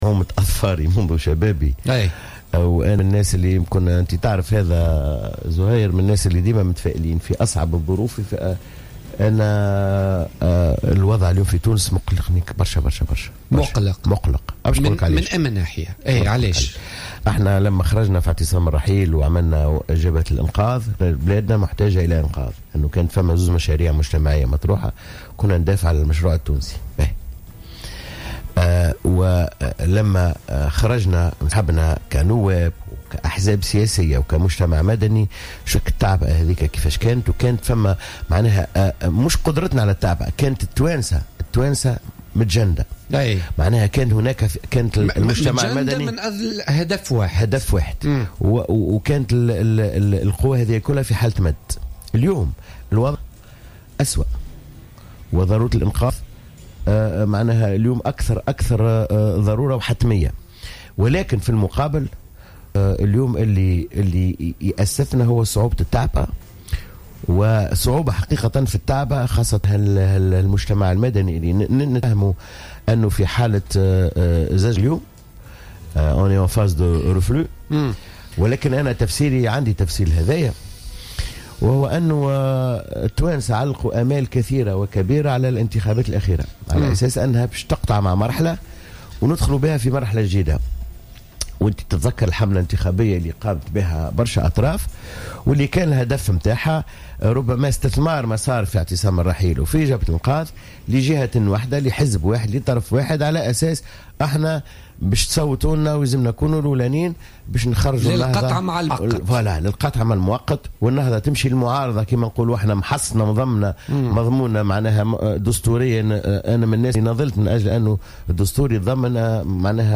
قال الأمين العام لحزب المسار سمير بالطيب ضيف بوليتيكا اليوم الثلاثاء 22 ديسمبر 2015 في رد على تصريح مية الجريبي أن الوضع السياسي في تونس اليوم بات مقلقا للغاية وأكثر من أي وقت مضى وذلك بسبب عدم القدرة على التعبئة وافتقاد تونس لمعارضة فاعلة وقوية .